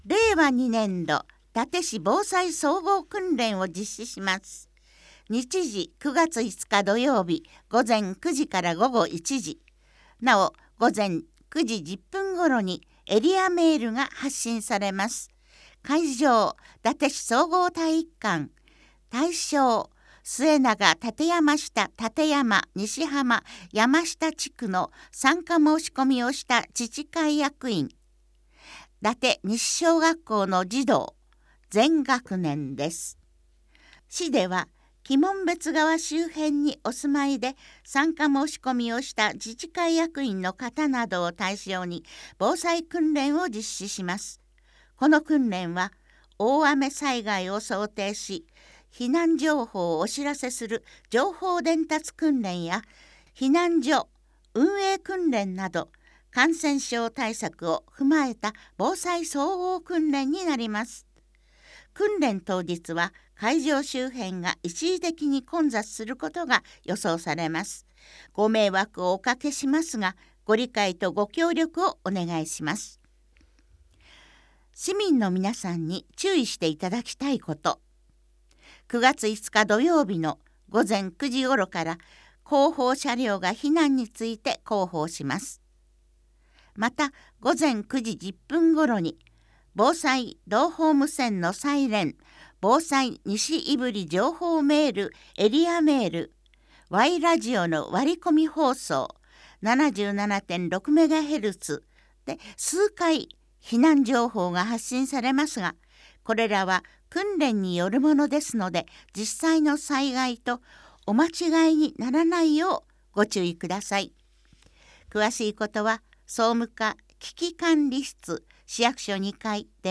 ■朗読ボランティア「やまびこ」が音訳しています